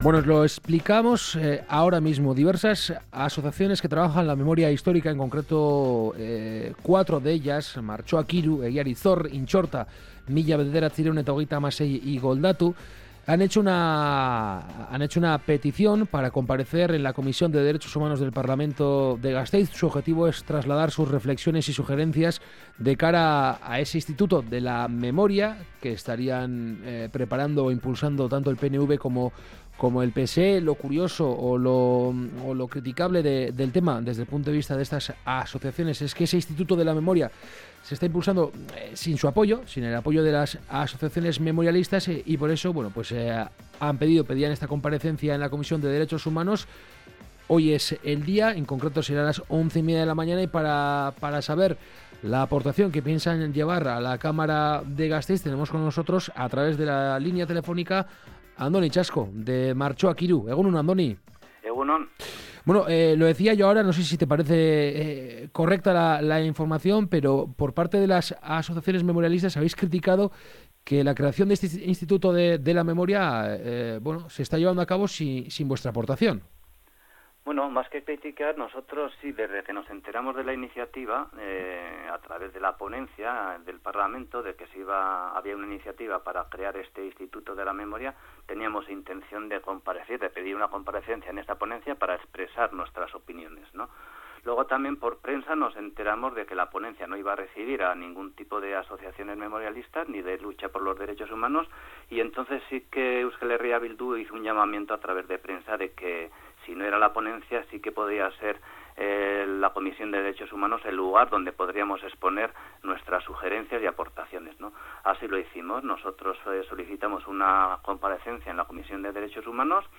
Gaur goizean, Oroimen Historikoa eta Giza Eskubideen alde lanean diharduten 4 elkartek (Martxoak 3, Intxorta 1937, Egiari Zor eta Goldatu) Gasteizeko Legebiltzarreko Giza Eskubide Batzordean parte hartuko dute. Bertan, EAJk eta PSEk bultzatu dute Memoriaren Institutuaz hitz egingo dute. Horren harira elkarrizketatu dugu